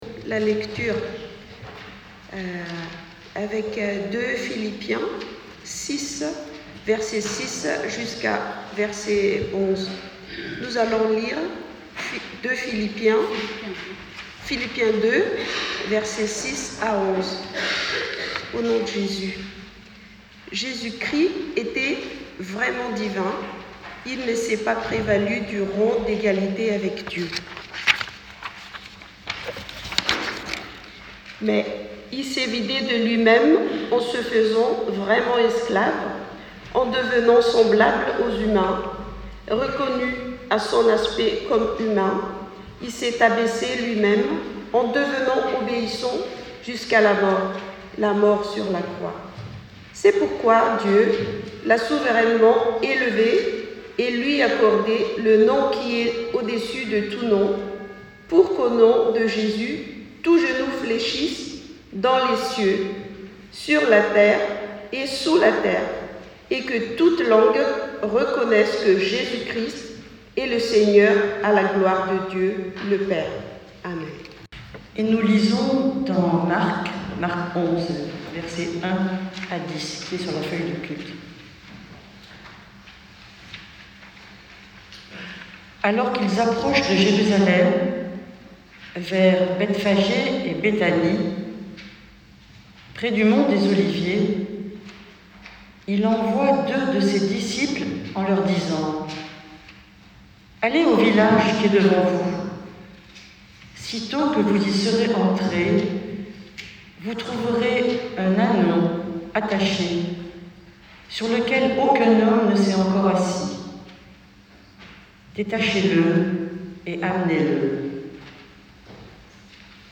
PRÉDICATION DU 24 MARS 2024.pdf (251.74 Ko) Audio lecture et prédication du 24 mars 2023 (Rameaux).mp3 (39.73 Mo)